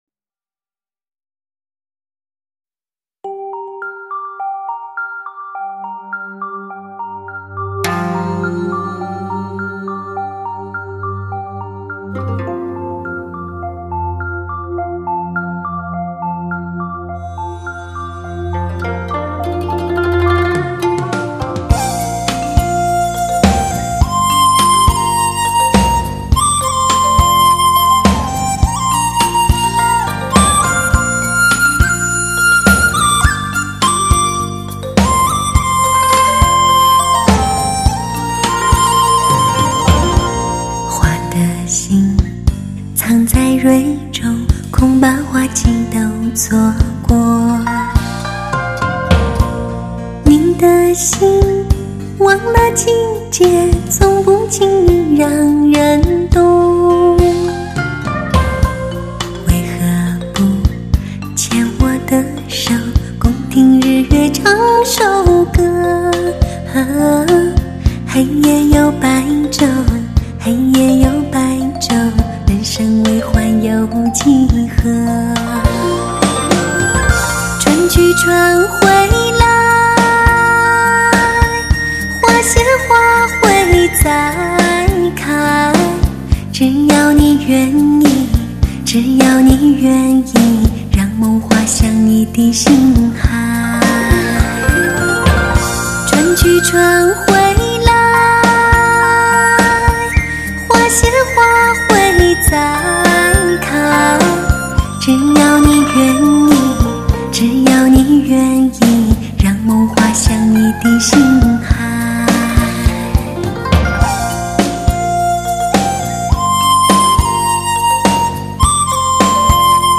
史上人声最甜美感情最丰富的女声 经典HI-END试音王，国际发烧音响协会权威推荐的专业测试盘